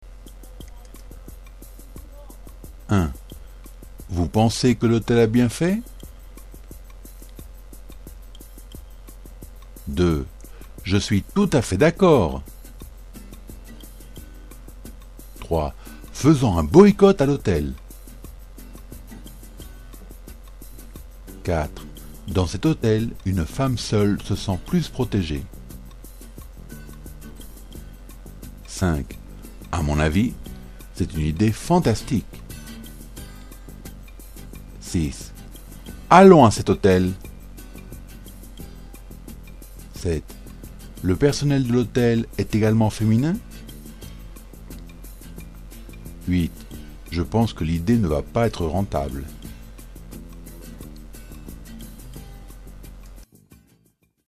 No tendrás el texto, puede que no sepas que significan, pero seguro que eres capaz de acertar de qué modalidad de oración hablamos.